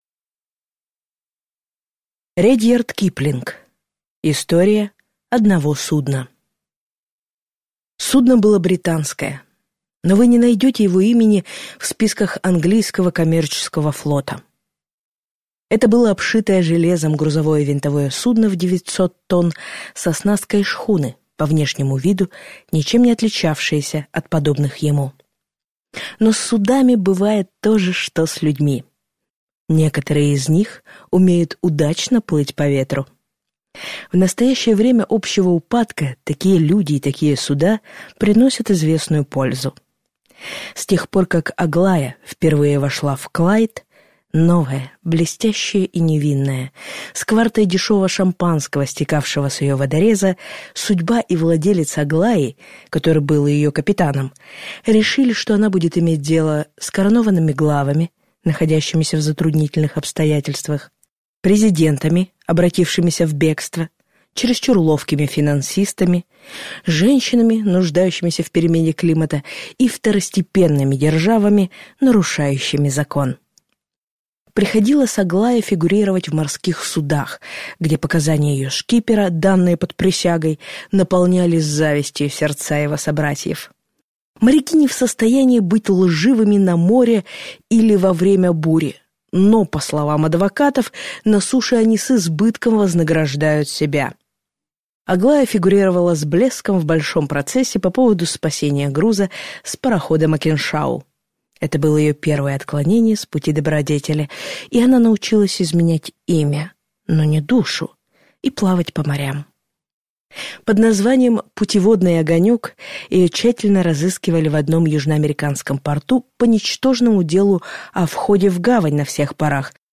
Аудиокнига История одного судна | Библиотека аудиокниг